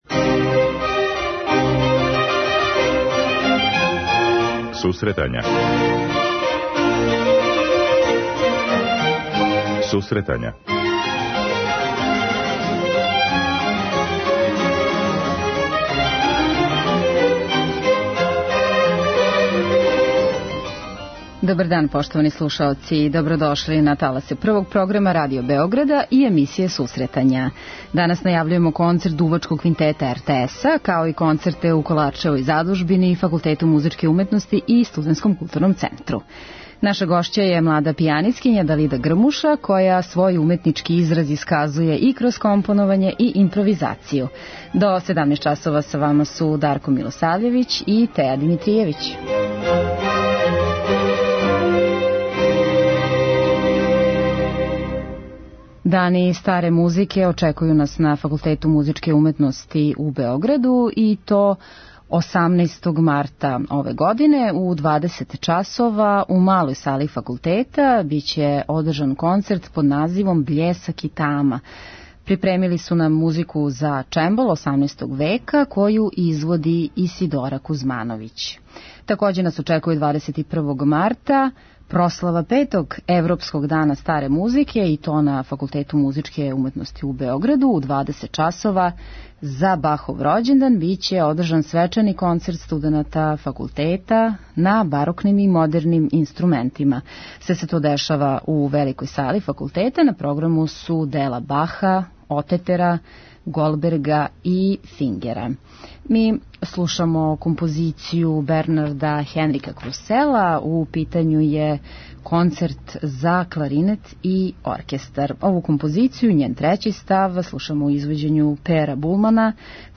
преузми : 10.78 MB Сусретања Autor: Музичка редакција Емисија за оне који воле уметничку музику.